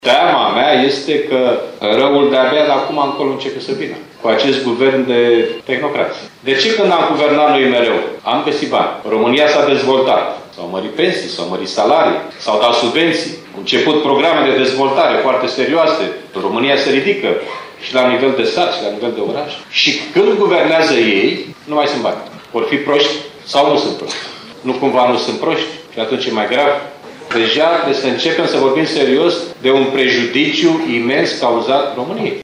Președintele PSD Liviu Dragnea a participat astăzi la lansarea candidaților din județul Suceava la alegerile locale.